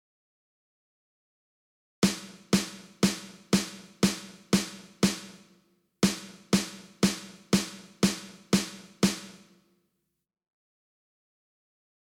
ちょっとこのリズムだけ、小太鼓（スネアドラムといいます）で叩いたものを聞いてみてください。